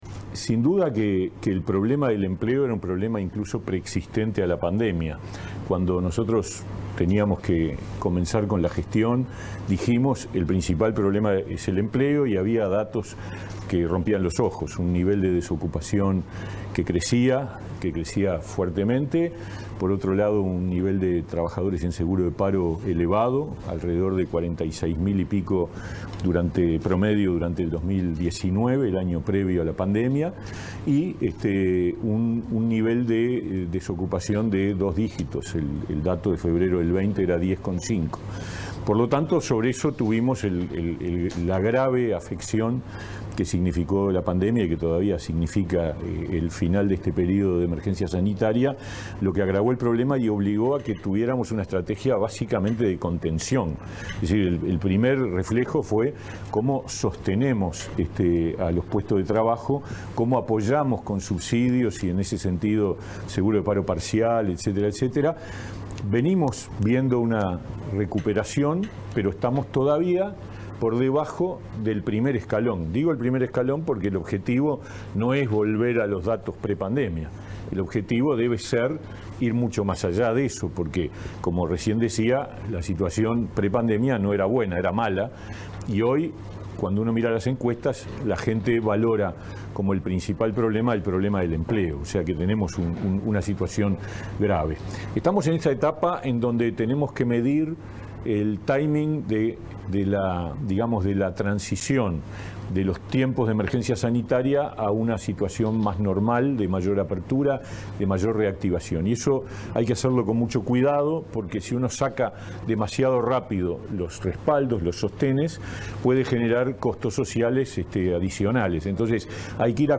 Este miércoles 18, el ministro de Trabajo, Pablo Mieres, disertó en un desayuno en línea de la Asociación de Dirigentes de Marketing del Uruguay (ADM)